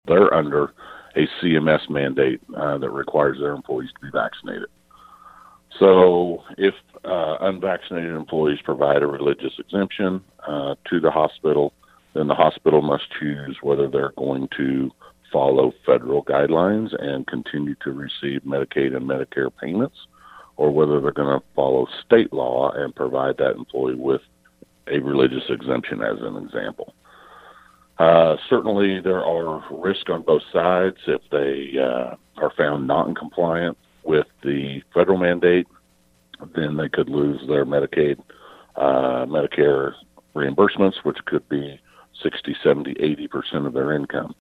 17th District Senator Jeff Longbine of Emporia voted against the bill, in part because he’s concerned businesses may be stuck between a rock and a hard place with the state and federal legislation now involved. He used Newman Regional Health as an example.